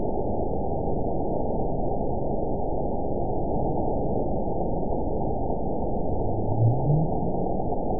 event 922344 date 12/30/24 time 02:03:40 GMT (4 months ago) score 9.64 location TSS-AB04 detected by nrw target species NRW annotations +NRW Spectrogram: Frequency (kHz) vs. Time (s) audio not available .wav